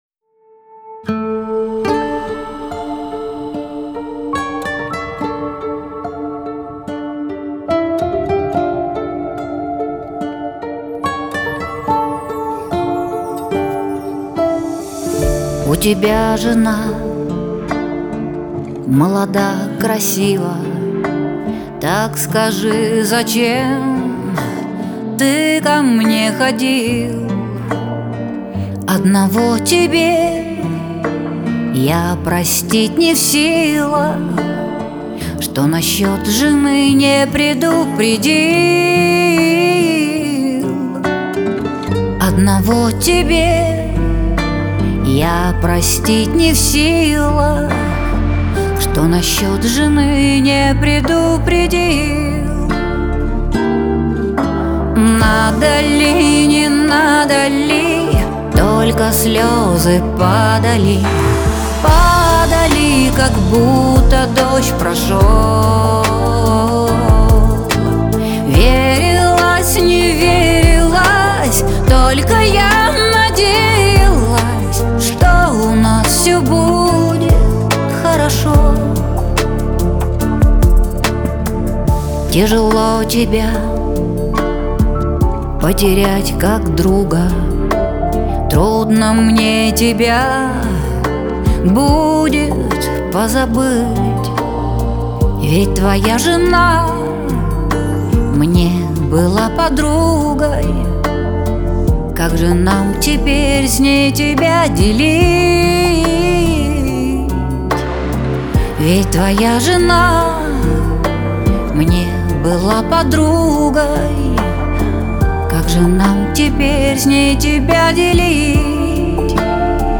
pop
эстрада